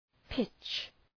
pitch Προφορά
{pıtʃ}